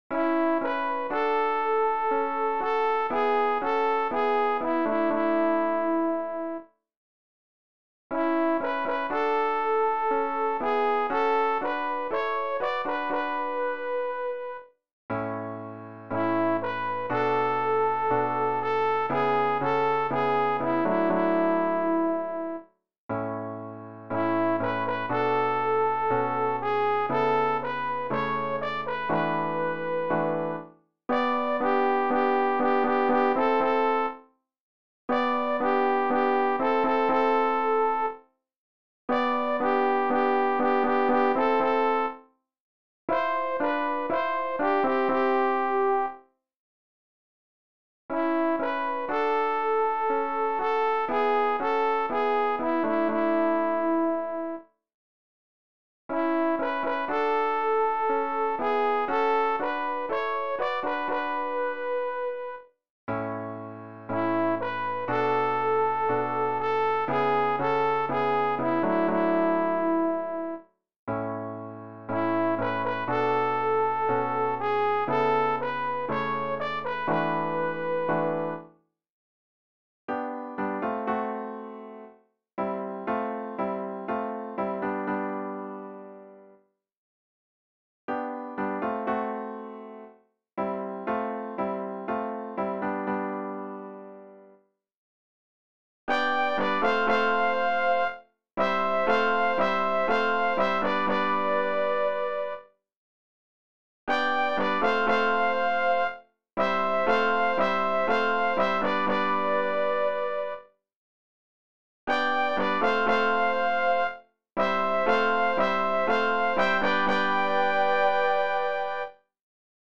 Übehilfen für das Erlernen von Liedern
Sopran Alt Tenor Bass